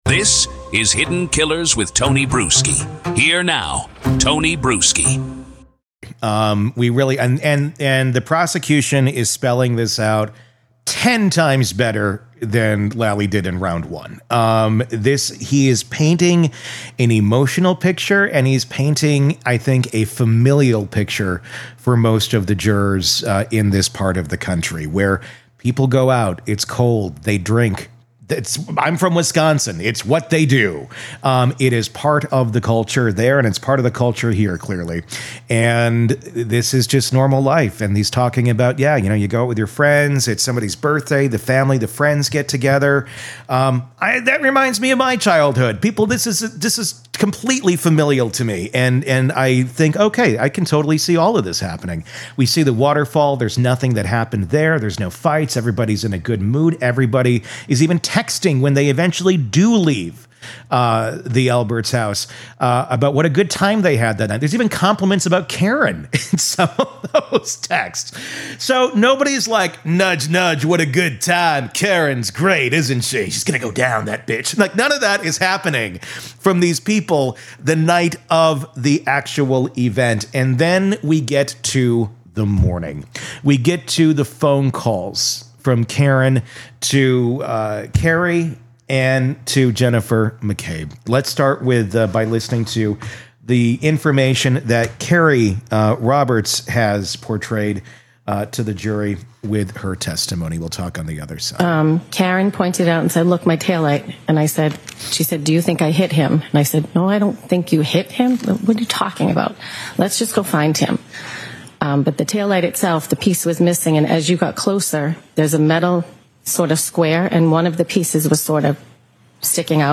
This conversation cuts through the courtroom drama and gets to the legal core: does this theory even pass the smell test?